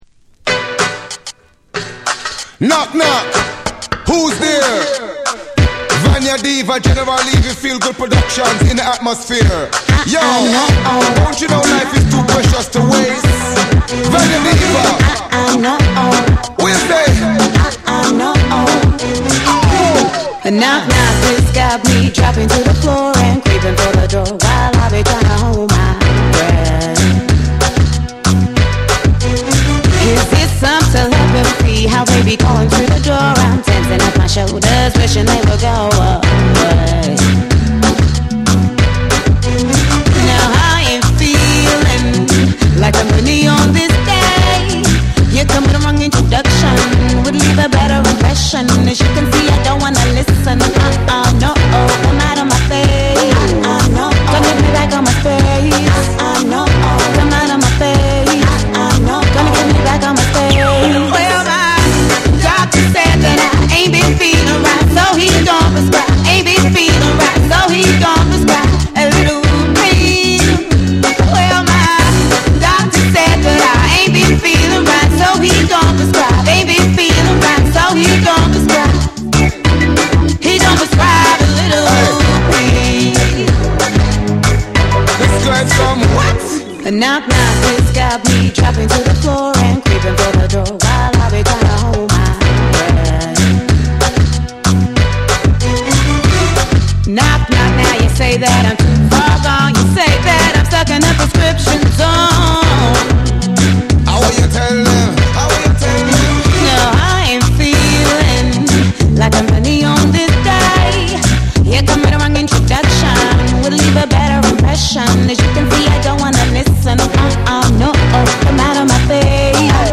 オリジナルはグルーヴィーでフューチャリスティックなダンスホール・トラック。
BREAKBEATS / REGGAE & DUB